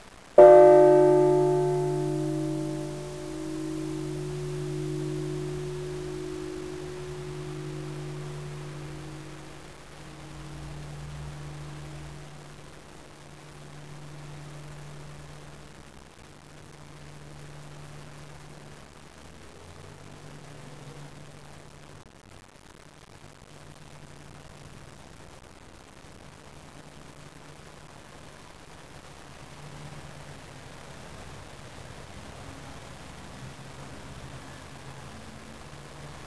In St. Salvatoris gibt es eine Bronzeglocke vom Ende des 17. Jahrhunderts. Bei ihr erklingen weitere Obertöne.
zellerfeld-bronze-mono22.wav